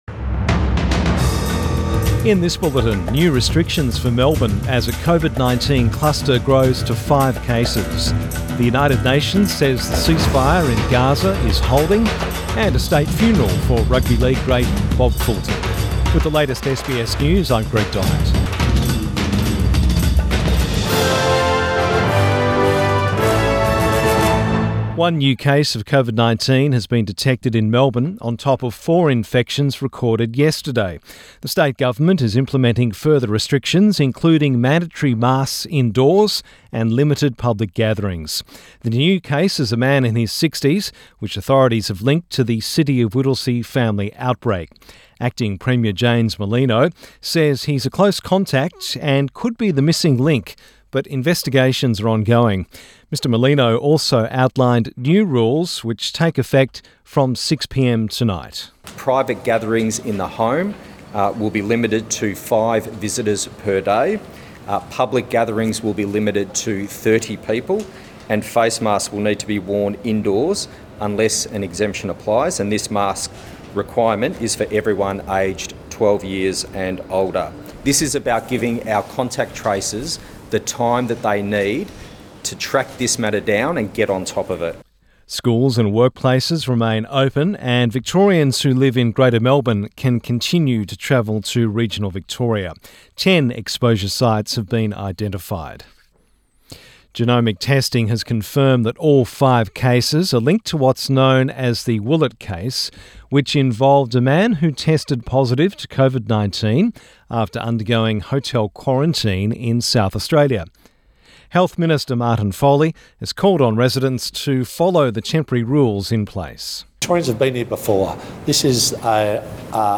Midday bulletin 25 May 2021